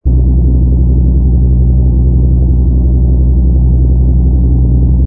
rumble_oe_h_fighter.wav